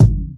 Kicks
It Blows My Mind Kick.wav